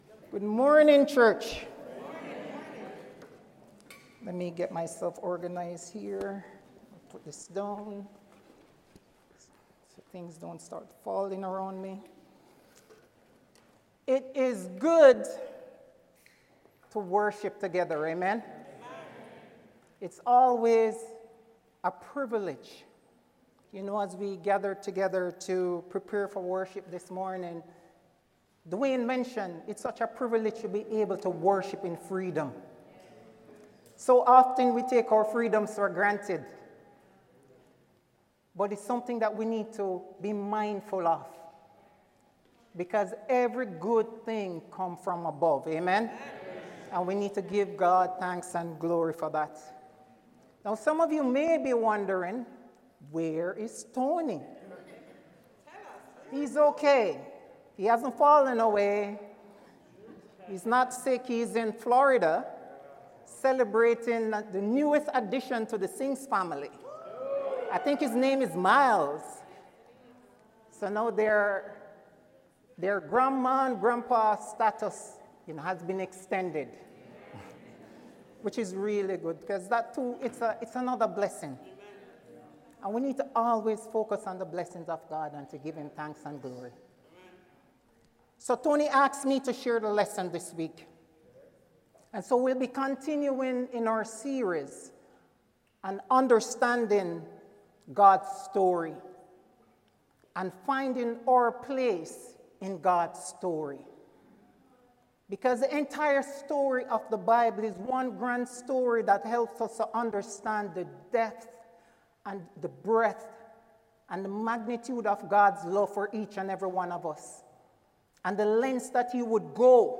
Podcast feed for sermons from Ottawa Church of Christ